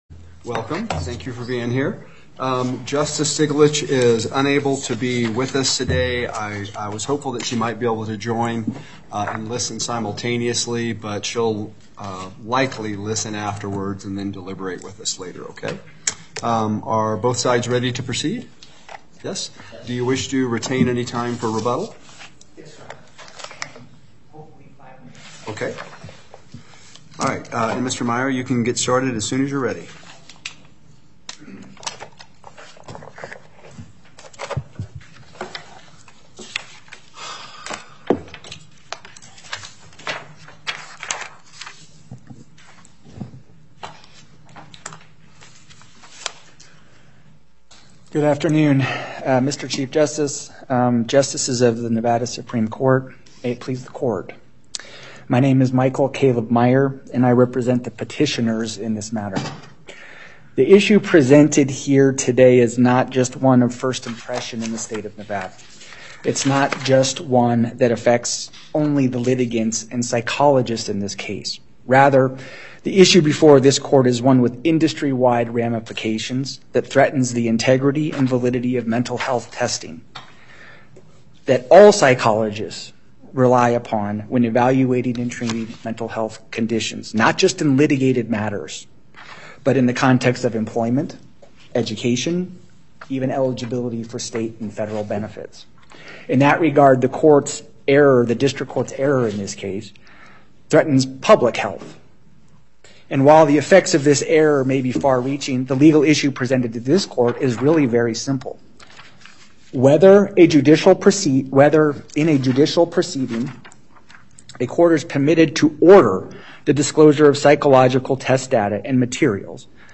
Before the En Banc Court, Chief Justice Herndon presiding Appearances